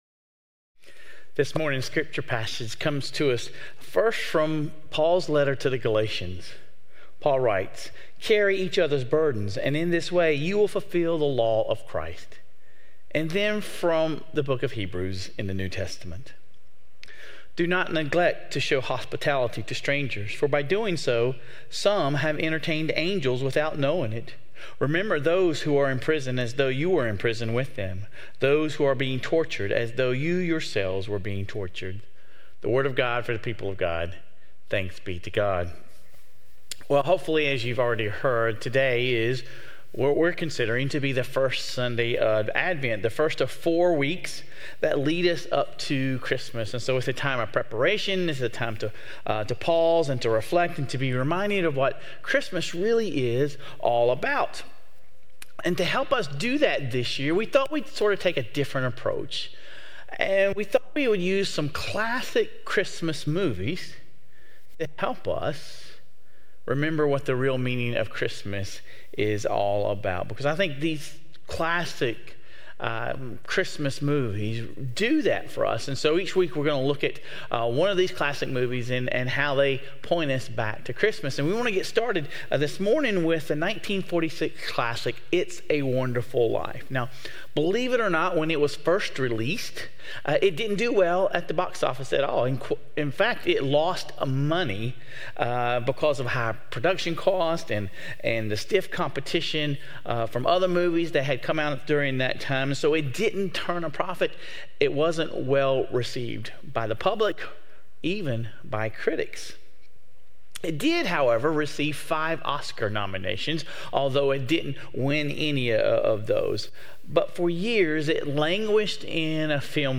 Sermon Reflections: Reflect on George Bailey's sacrificial choices in the movie.